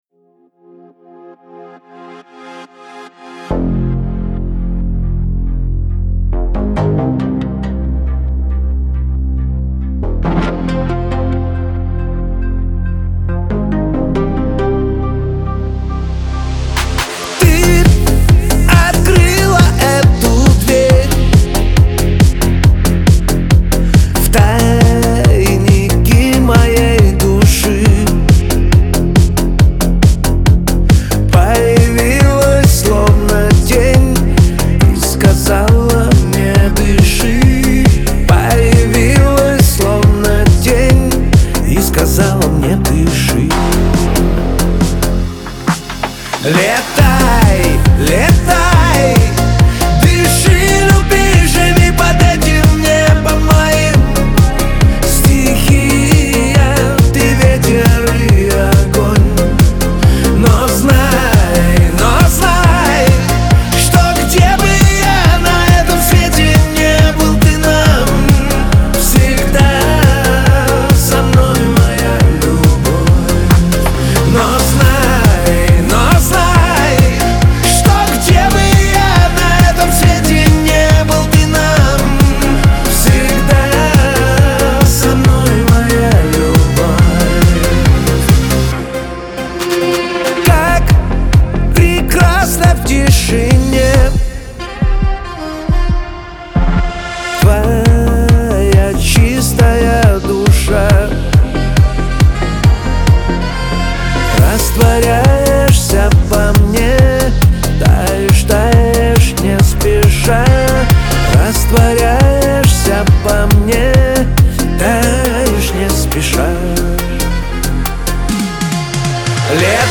диско , pop , Лирика , эстрада